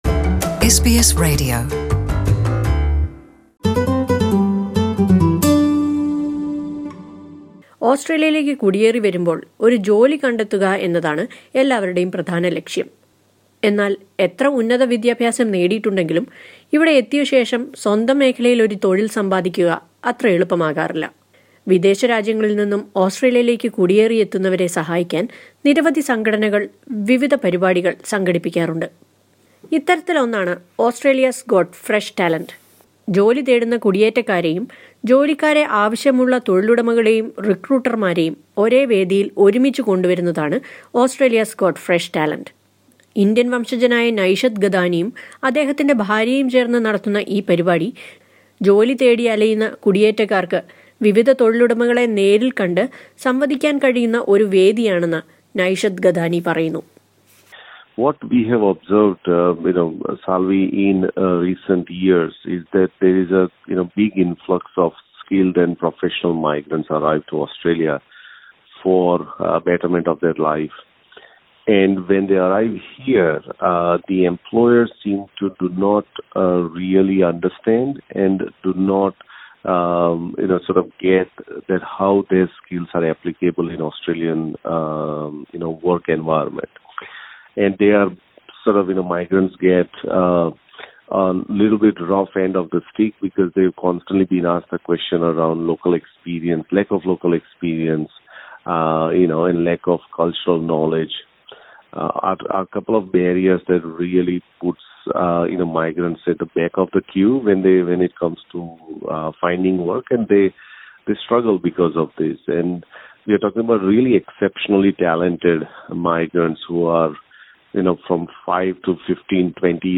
A new initiative with the name Australia's got Fresh Talent has launched in Melbourne giving an opportunity for the migrants to try their hands in the job market. Listen to a report on this.